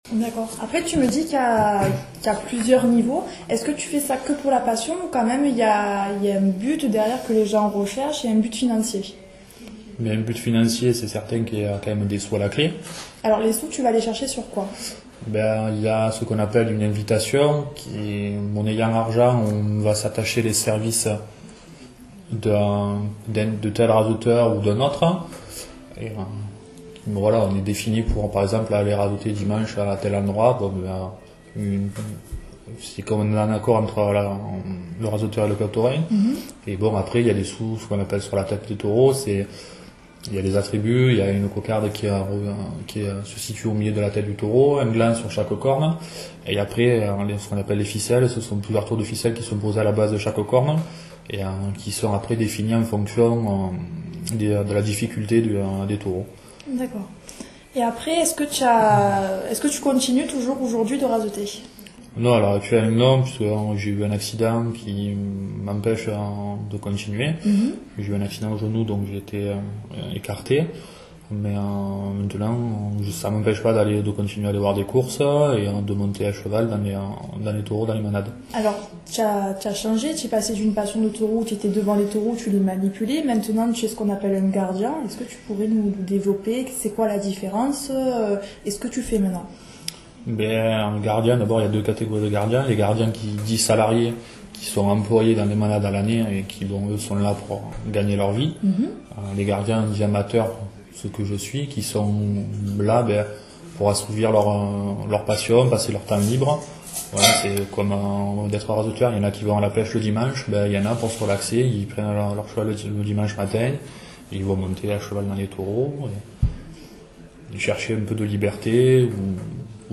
9. c’est quoi, la différence ? : construction très orale, à la place de « Quelle est la différence ? ».
11. tout dépend le travail: cette construction est très utilisée dans le sud de la France.